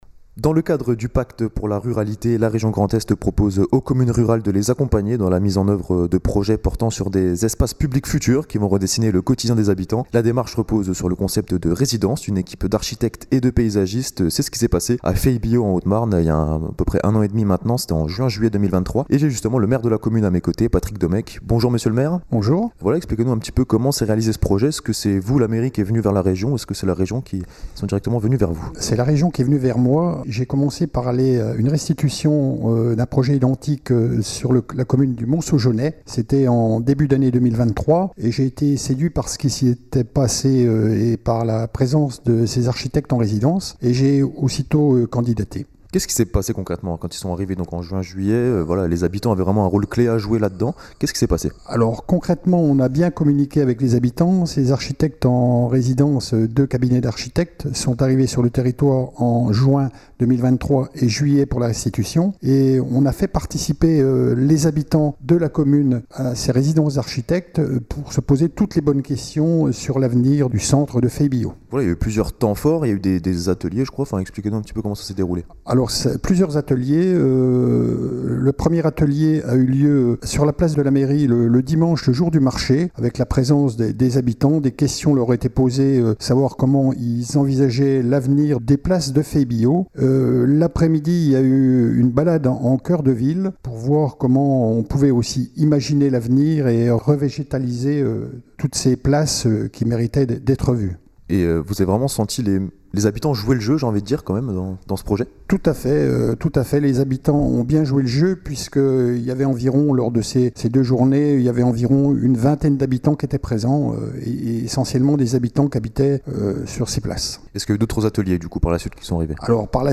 Reportage 3